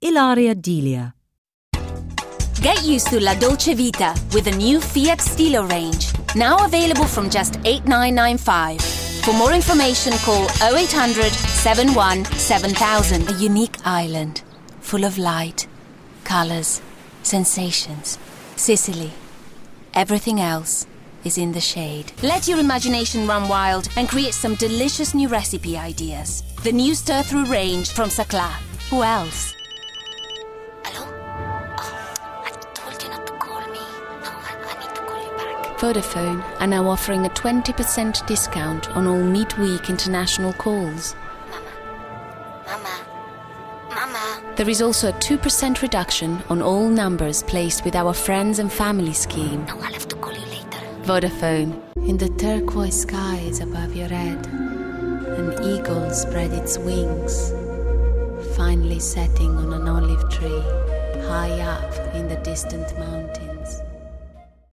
Italian voiceover artist